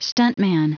Prononciation du mot stuntman en anglais (fichier audio)
Prononciation du mot : stuntman